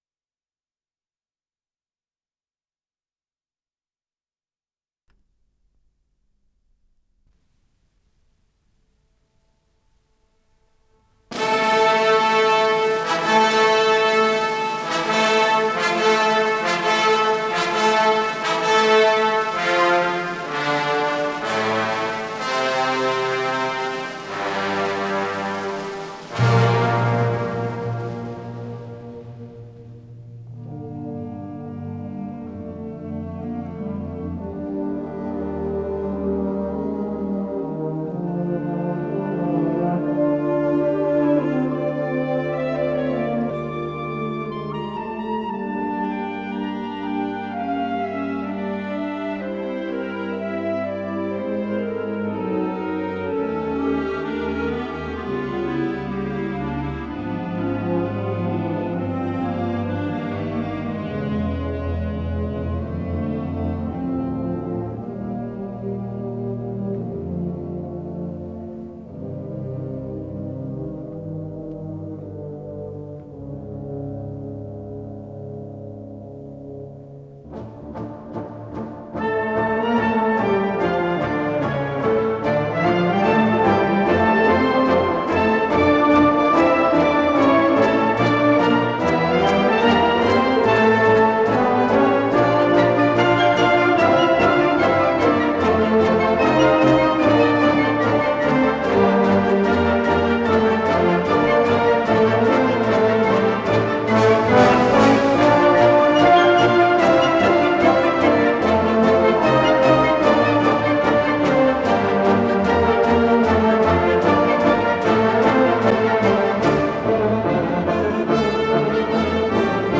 с профессиональной магнитной ленты
ПодзаголовокФа мажор
ВариантДубль моно